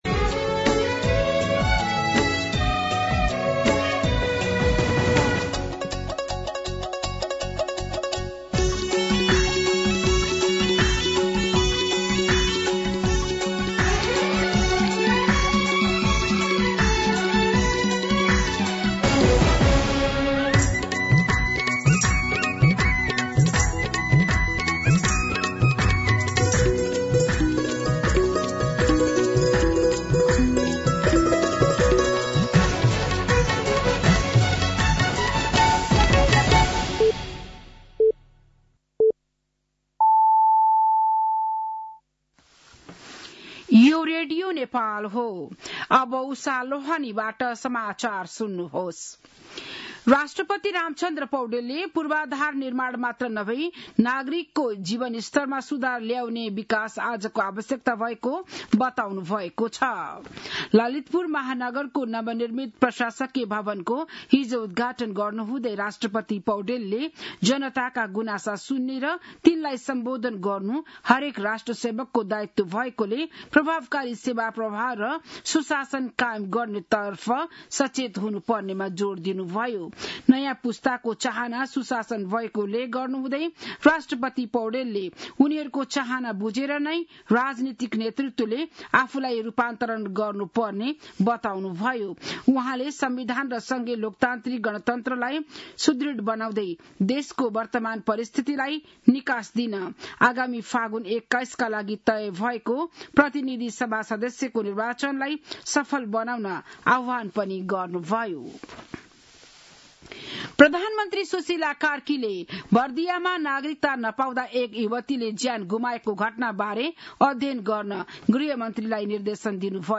बिहान ११ बजेको नेपाली समाचार : २८ असार , २०८२